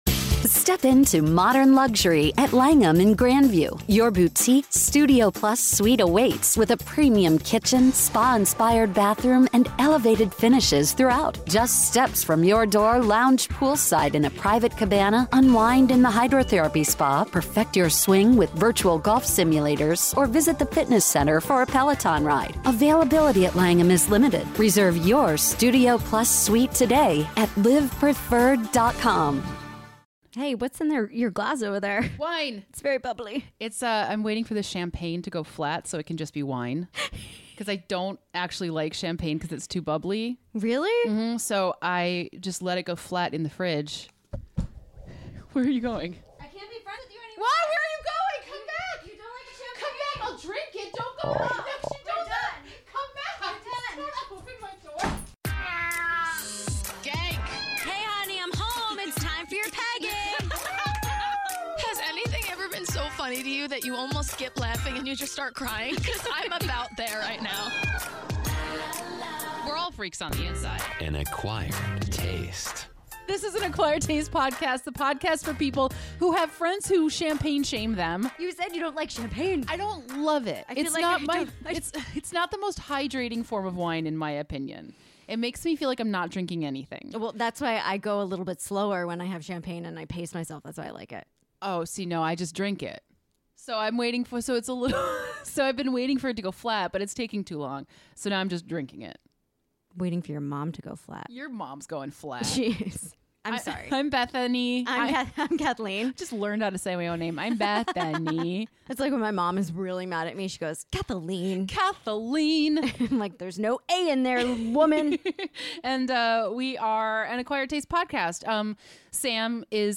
The ladies are offering up some weird news stories that you can use to change the subject at your family Thanksgiving when one of your relatives brings up politics or your questionable life choices!